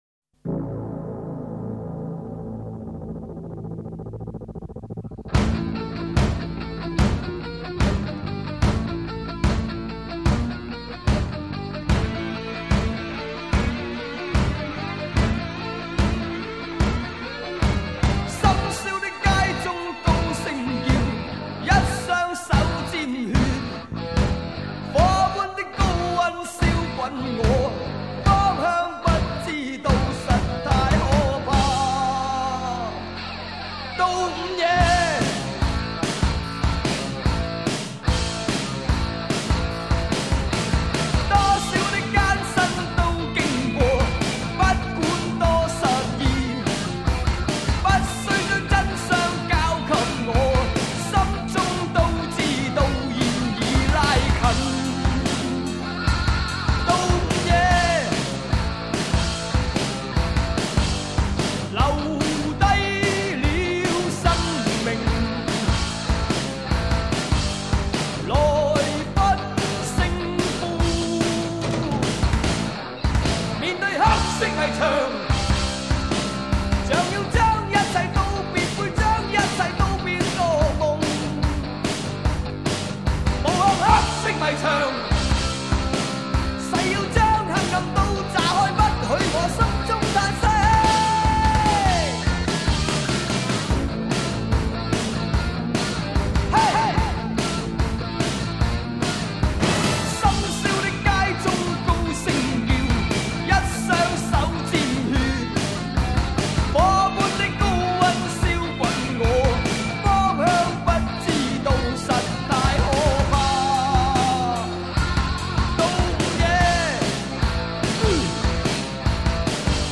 风格：流行/Pop 摇滚/Rock